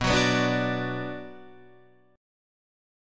Bm chord